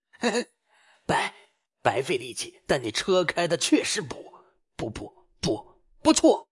Urkomisches KI-Büro-Voiceover
Text-zu-Sprache
Trockener Humor
Sarkastischer Ton